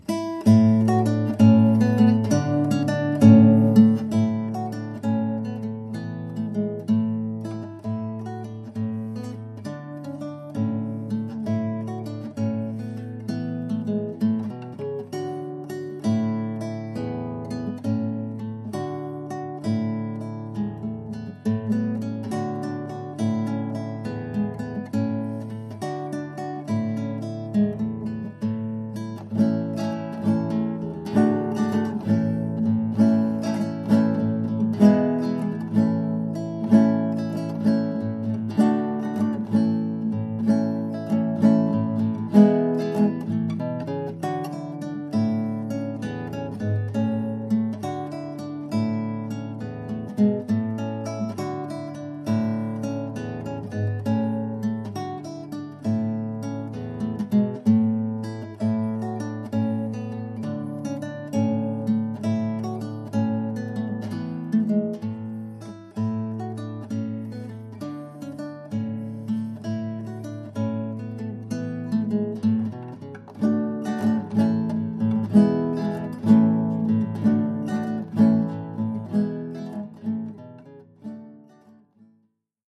Oeuvre pour guitare solo.